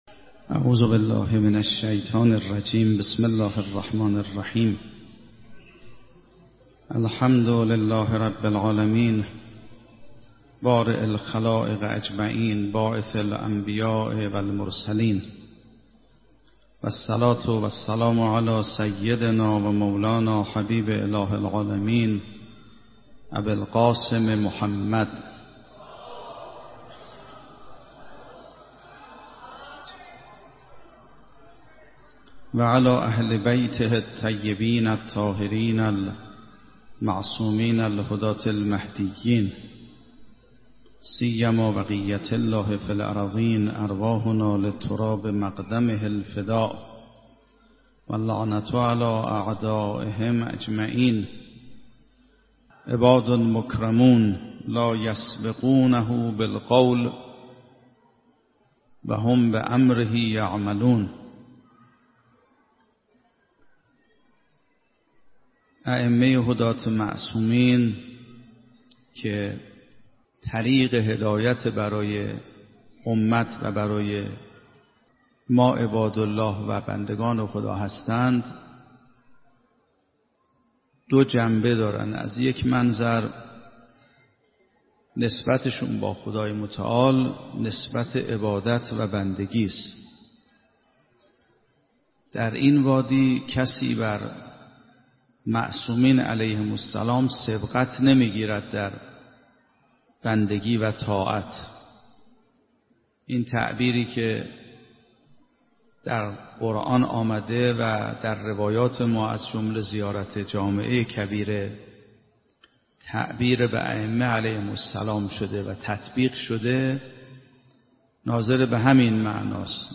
سخنرانی آیت الله سید محمدمهدی میرباقری با عنوان معرفت به ائمه معصومین (ع)